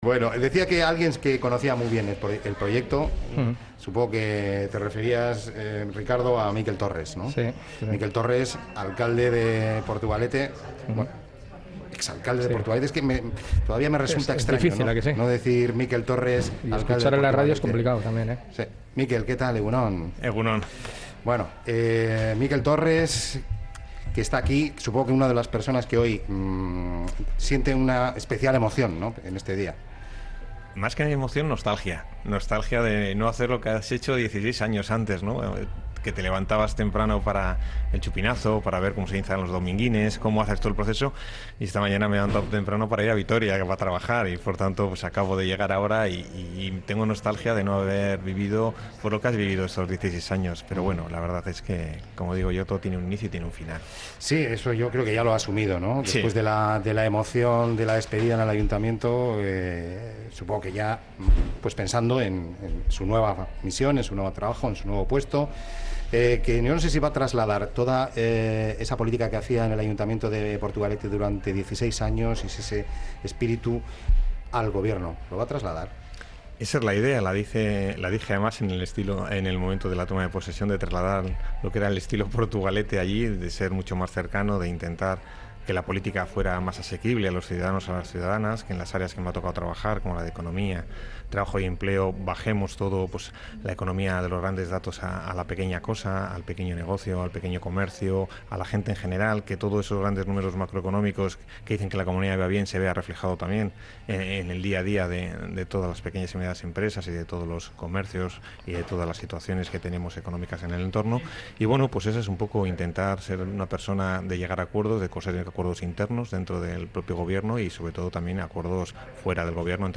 Mikel Torres en el Hotel Puente Colgante. ONDA VASCA